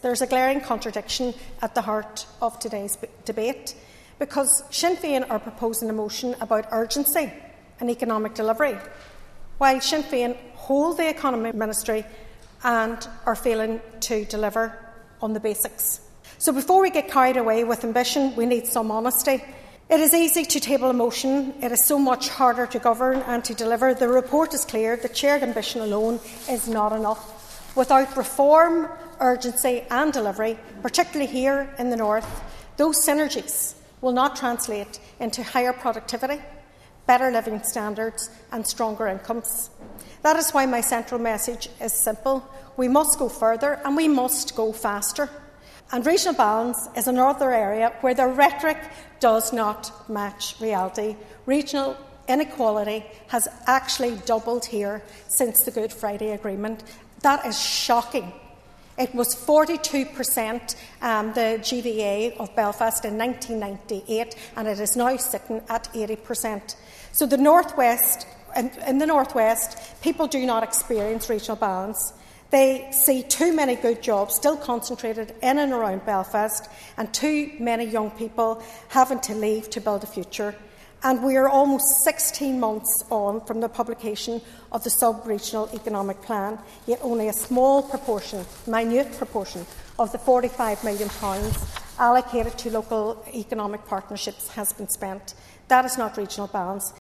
Speaking on a Sinn Fein motion noting the ESRI’s report Assessing Economic Trends on the Island of Ireland, the SDLP Economy Spokeserson welcomed the motion and the report, but said progress on developing the All Island Economy is not fast enough.
Sinead McLaughlin told the Assembly that regional balance is widening rather than lessening under Sinn Fein’s stewardship of the economy…….